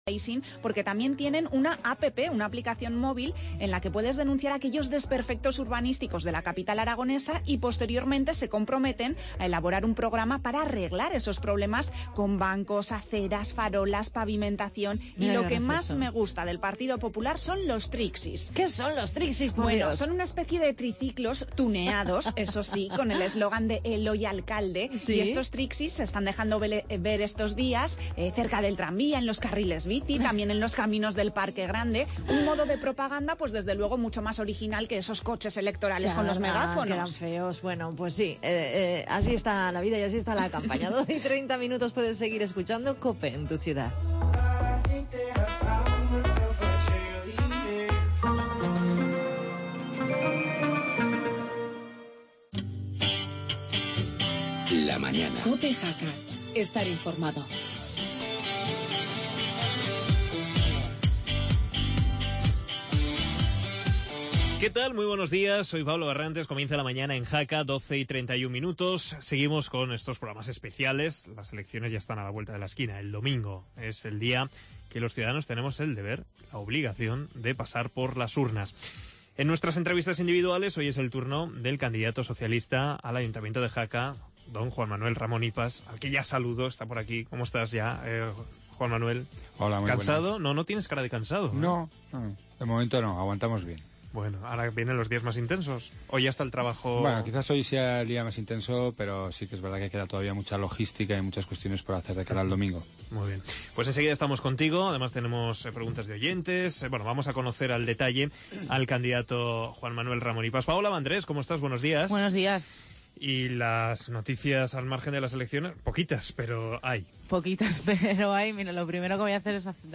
AUDIO: Actualidad de Jaca y entrevista al candidato del PSOE en Jaca Juan Manuel Ramón Ipas.